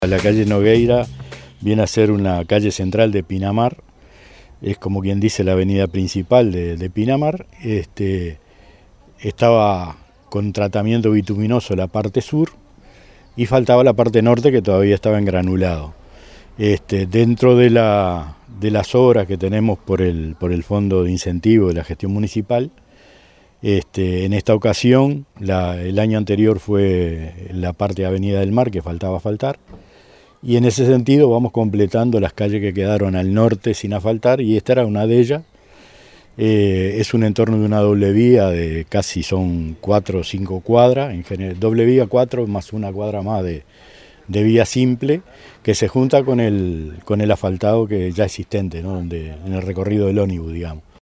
alcalde_de_salinas_oscar_montero.mp3